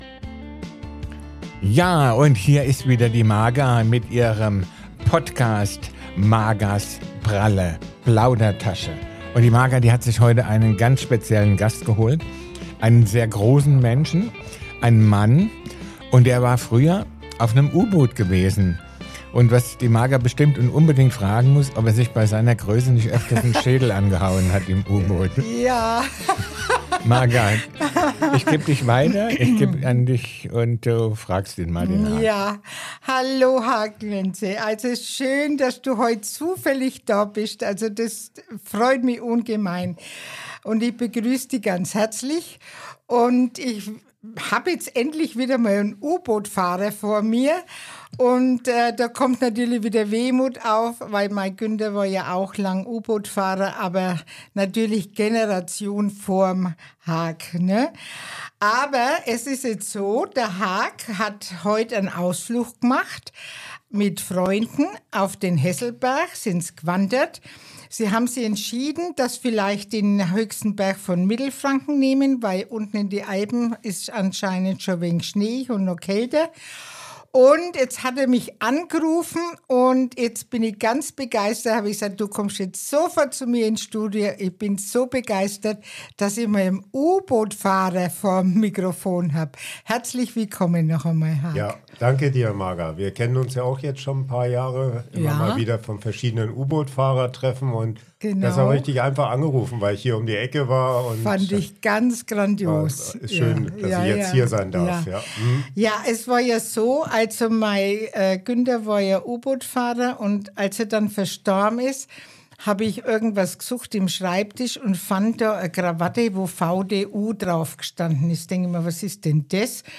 Beschreibung vor 2 Monaten In dieser Folge begrüße ich einen ganz besonderen Gast: einen echten U-Boot-Fahrer und langjährigen Weggefährten aus dem Verband der deutschen U-Boot-Fahrer.